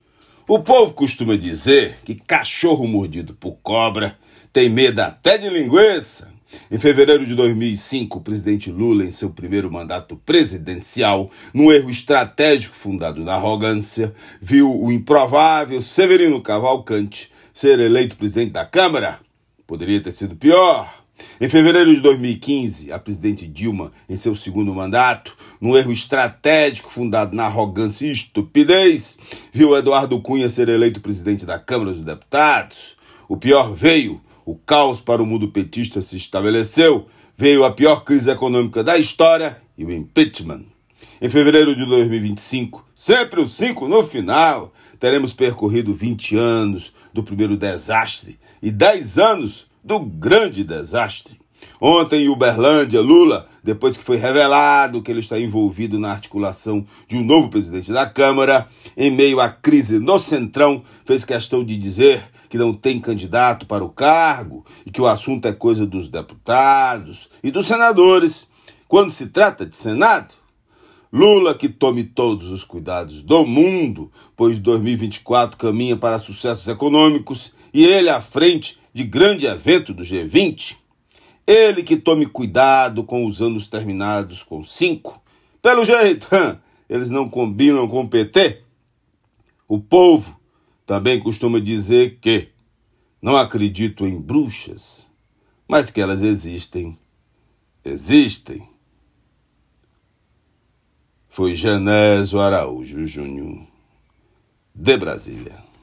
Comentário desta sexta-feira (06/09/24)